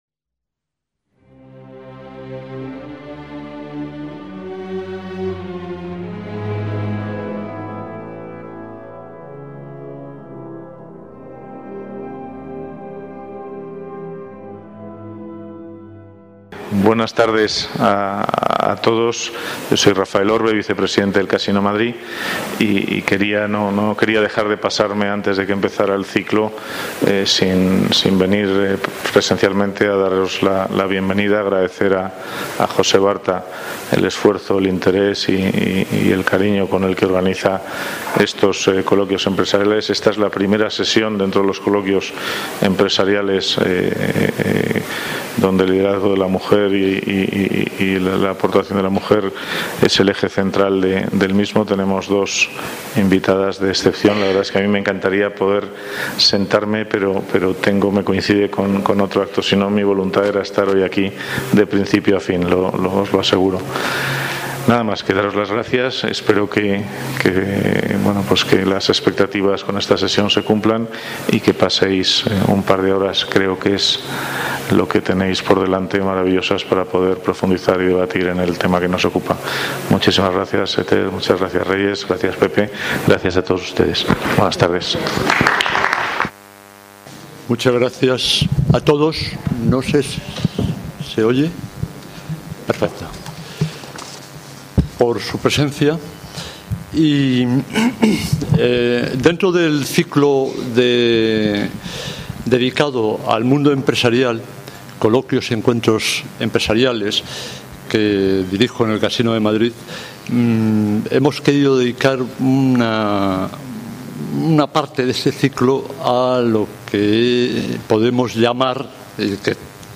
Coloquio Empresarial sobre liderazgo de la mujer
Casino de Madrid
ACTOS EN DIRECTO